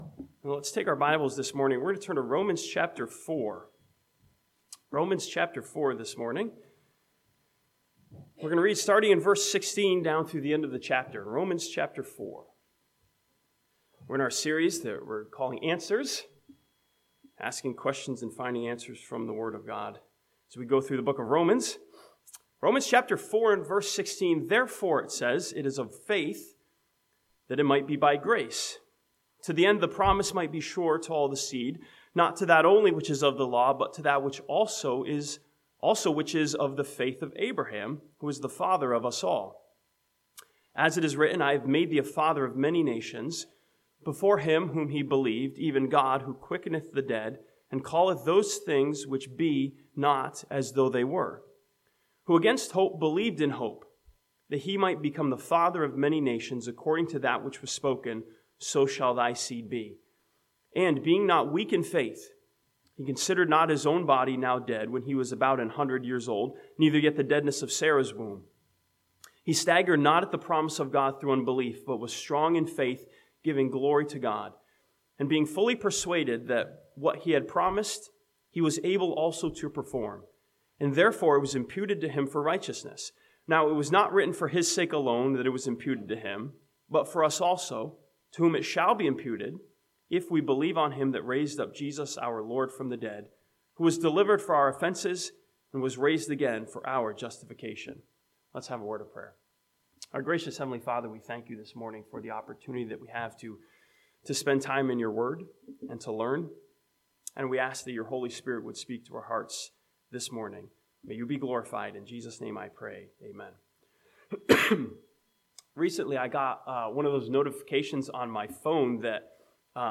This sermon from Romans chapter 4 challenges us with a question of improvement as we seek to grow in our Christian life.